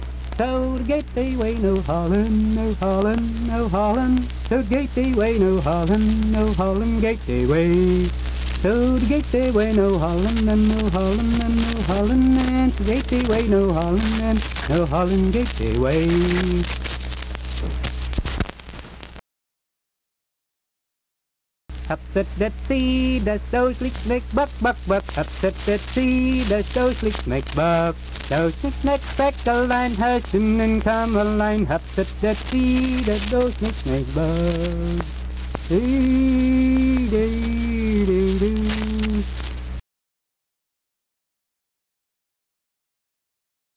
Unaccompanied vocal performance
Ethnic Songs
Voice (sung)
Wood County (W. Va.), Vienna (W. Va.)